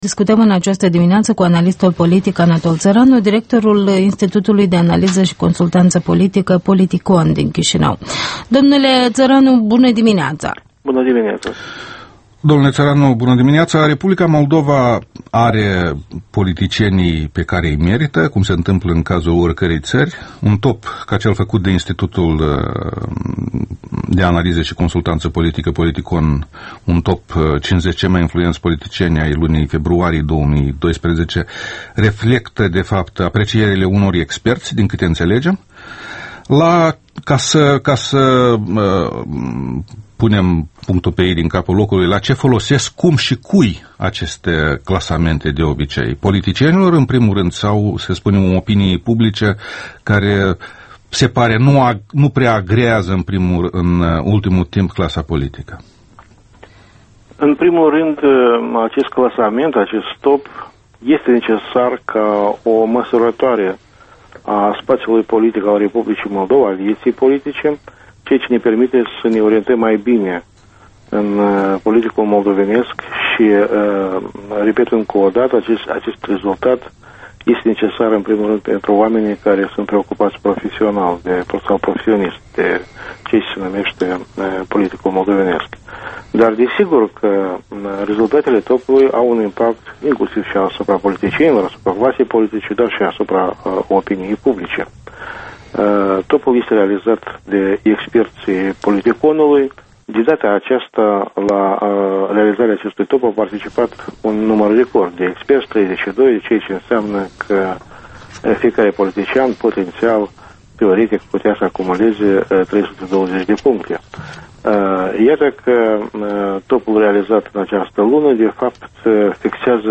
Interviul dimineții la Europa Liberă